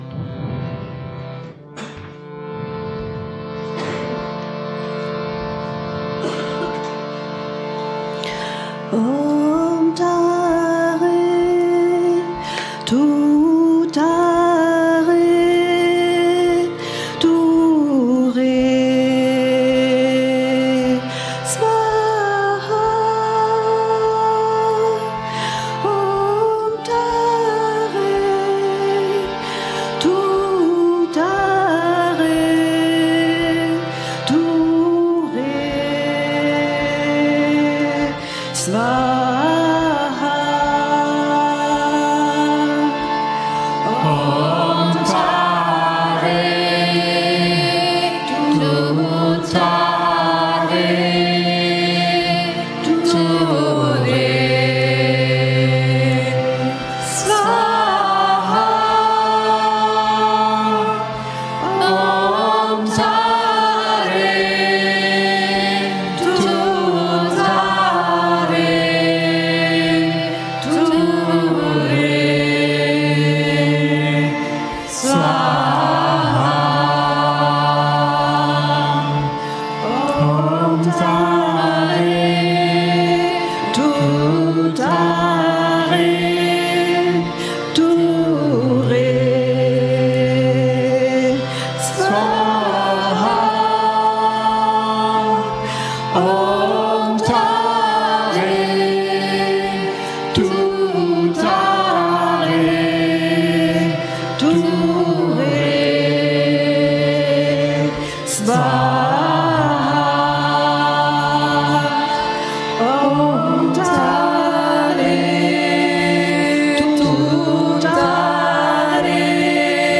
Shiva Shambho is a kirtan that sings of Shiva. Shiva means the loving one, the kind one.